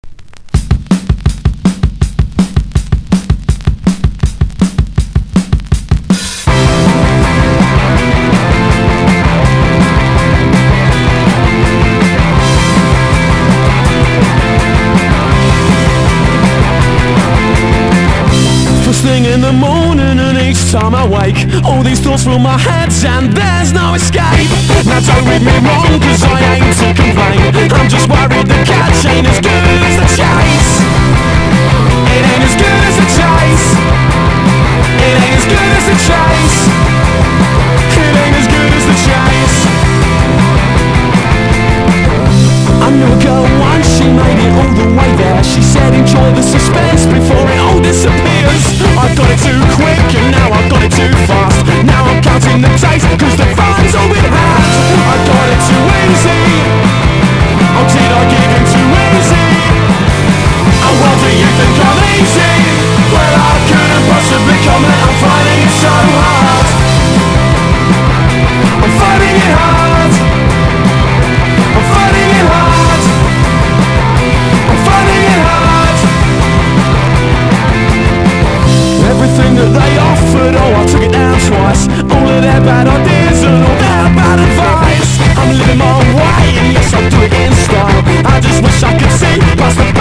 1. 00S ROCK >
GARAGE ROCK